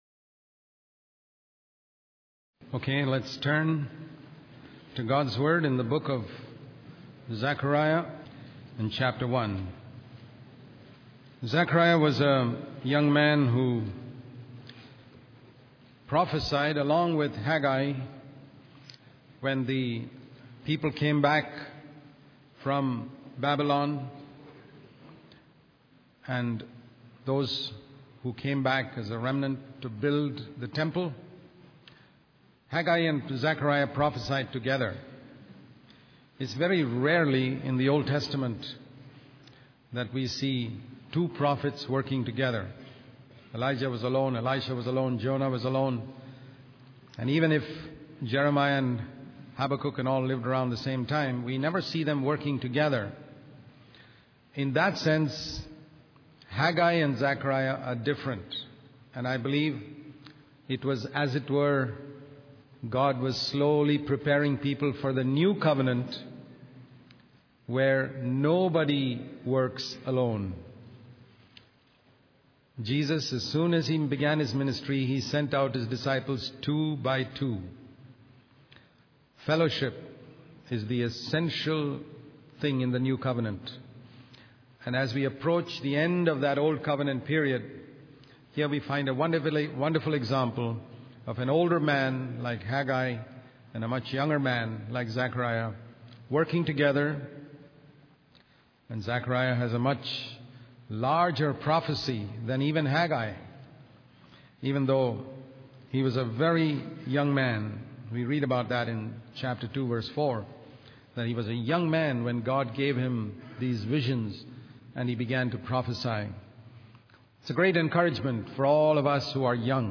In this sermon, the preacher discusses various visions and messages from the Lord. He emphasizes the importance of true fasting and worship that is focused on pleasing God rather than oneself.